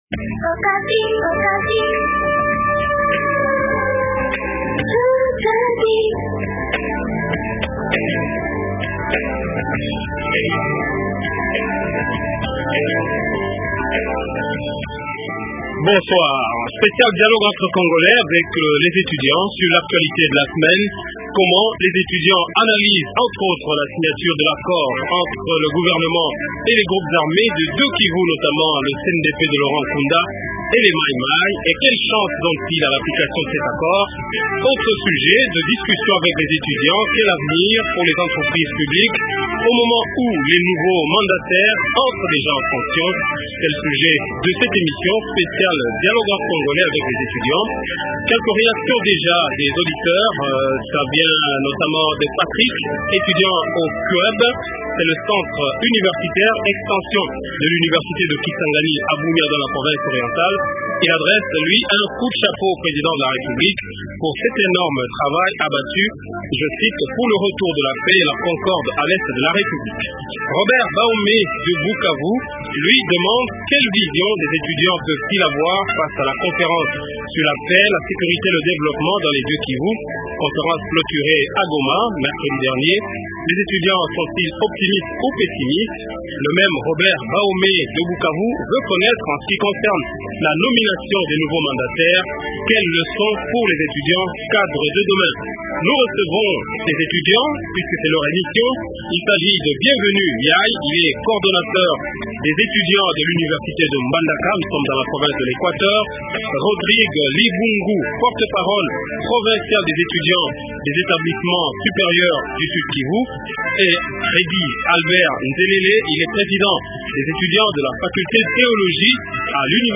Spécial Dialogue entre congolais avec les étudiants sur l’actualité de la semaine